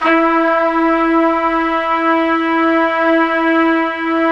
Index of /90_sSampleCDs/Roland LCDP06 Brass Sections/BRS_Tpts mp)f/BRS_Tps Velo-Sw